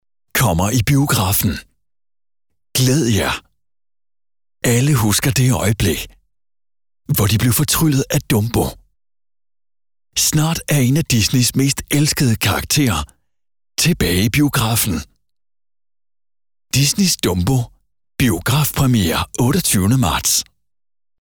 Male
Authoritative, Confident, Cool, Corporate, Engaging, Friendly, Natural, Reassuring, Sarcastic, Smooth, Soft, Warm, Versatile
Microphone: Neumann TLM 102
Audio equipment: pro sound booth